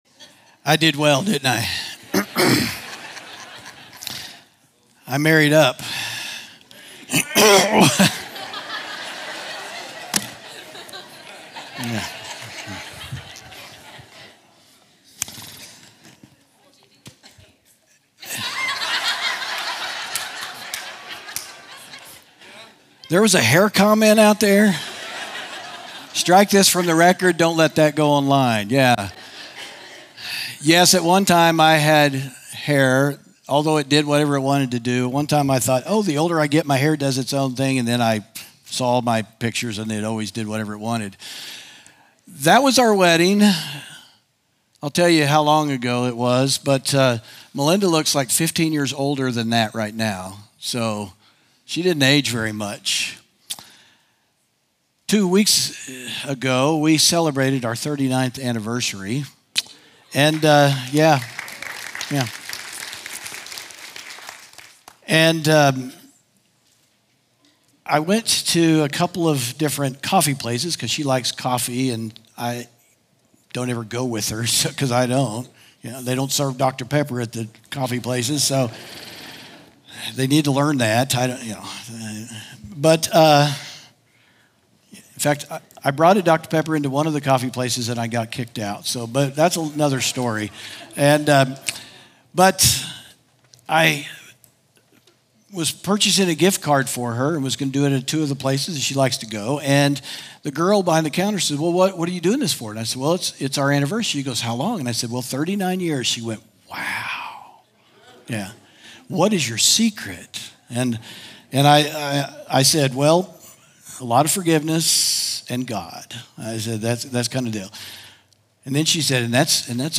sermon audio 0208.mp3